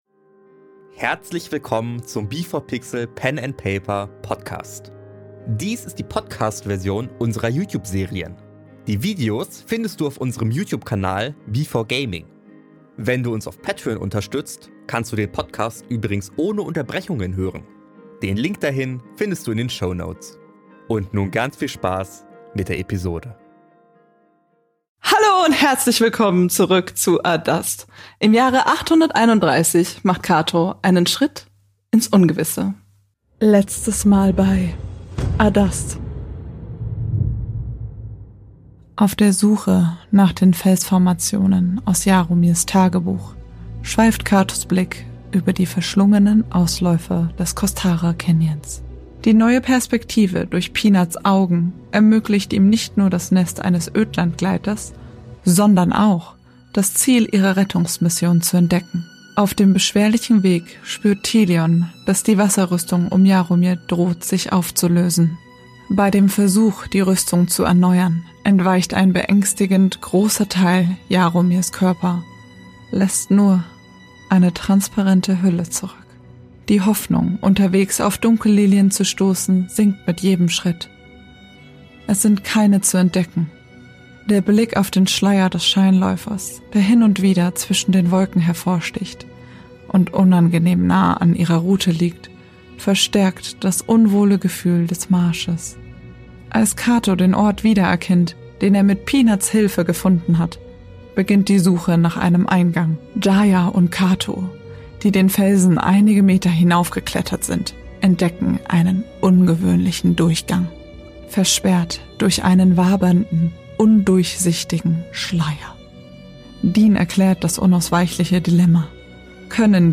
Dies hier ist die Podcast-Version mit Unterbrechungen.
In dieser Serie entstehen aufgrund ihrer improvisatorischen Art immer wieder Situationen mit verschiedensten Themen und Inhalten, die in euch ungewollte Erinnerungen oder auch Gefühle hervorrufen können und generell schwerer zu verarbeiten sind.